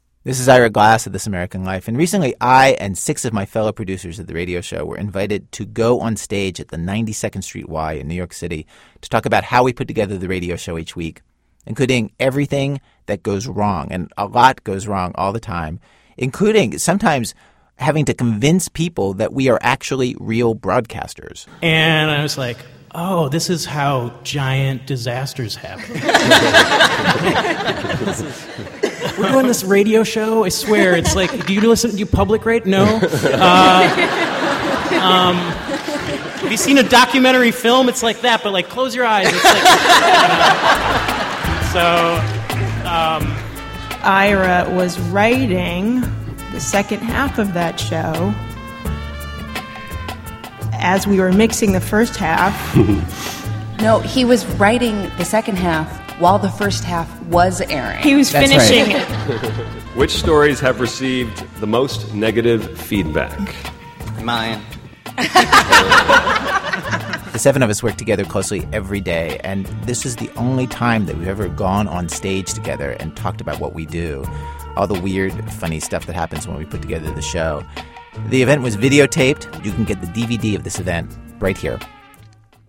You can listen to Ira Glass describe the DVD below.